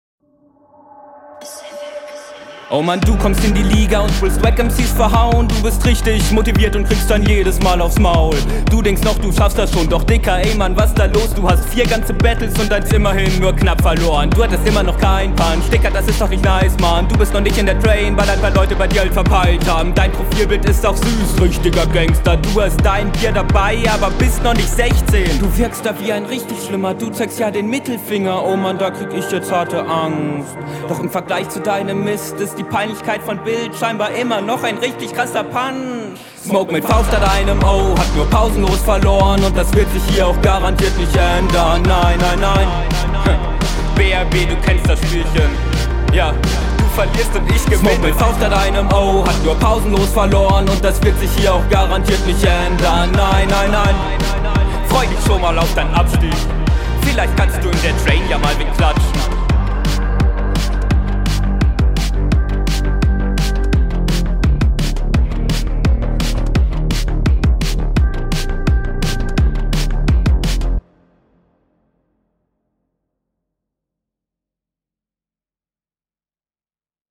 bis zu den triolen hast du voll nice gerappt. tu dir das nicht an so …